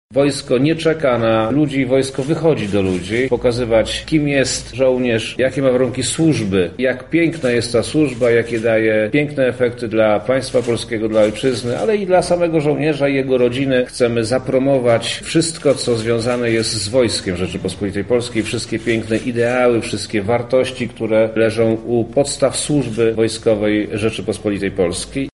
To jest okazja do poznania wojska – mówi Wojewoda Lubelski Przemysław Czarnek: